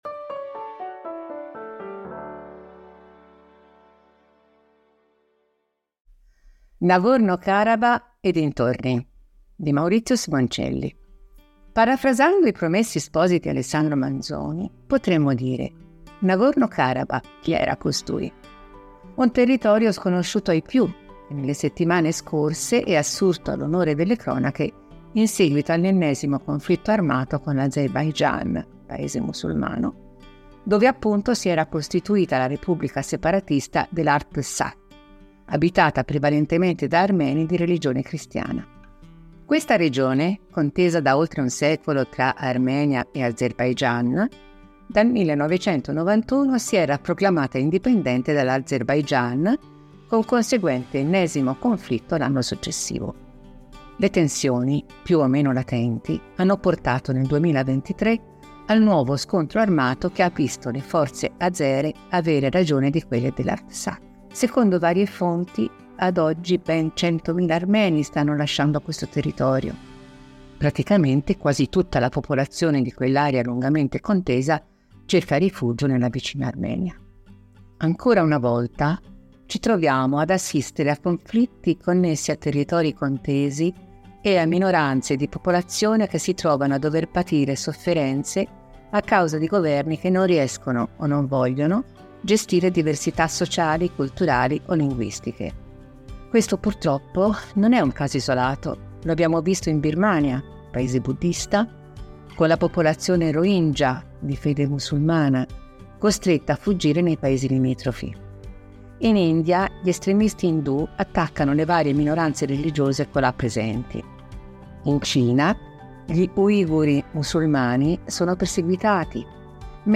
Per ogni numero, ci sarà una selezione di articoli letti dai nostri autori e collaboratori.
Al microfono, i nostri redattori e i nostri collaboratori.